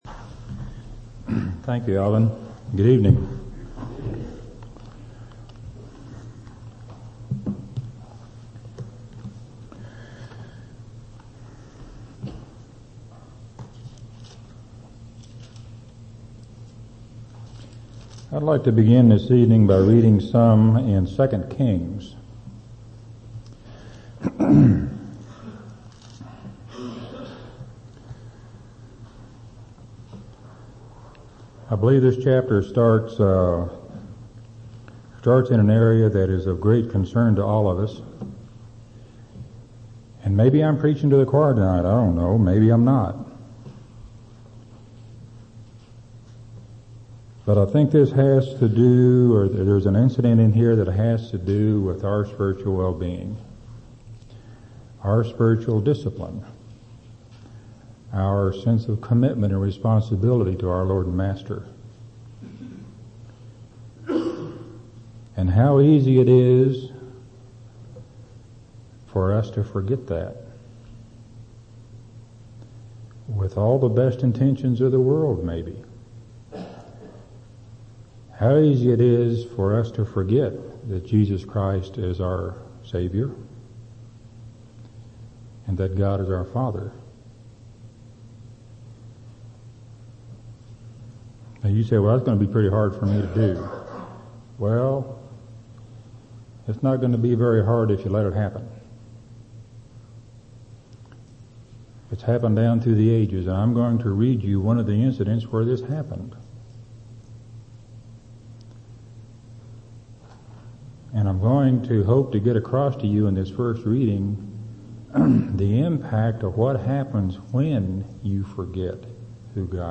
2/2/2003 Location: Temple Lot Local Event